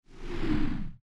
woosh.mp3